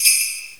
soft-hitfinish.mp3